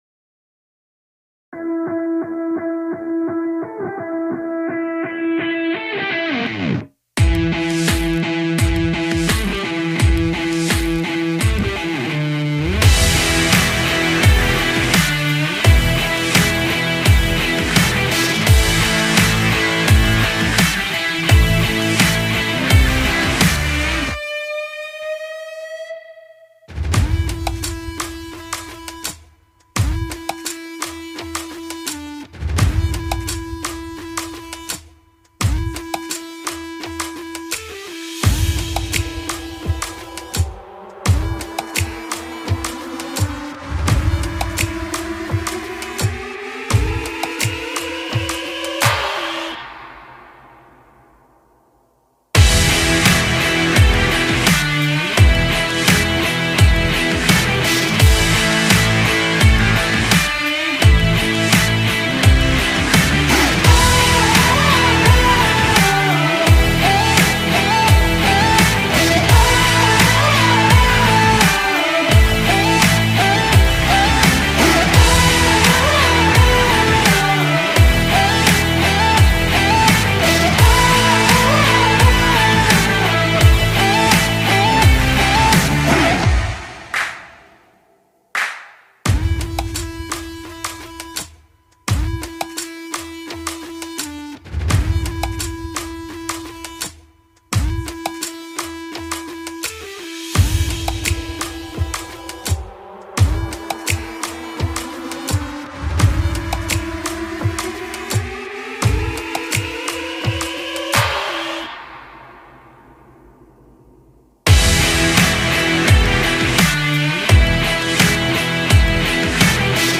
tema dizi müziği, heyecan aksiyon enerjik fon müziği.